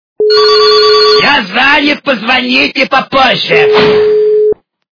» Звуки » Смешные » Еврейский голос - Я занят, позвоните попозже!
При прослушивании Еврейский голос - Я занят, позвоните попозже! качество понижено и присутствуют гудки.
Звук Еврейский голос - Я занят, позвоните попозже!